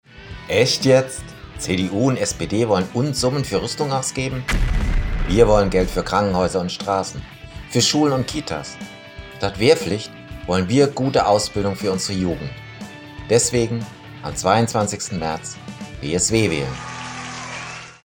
Wahlwerbespots Hörfunk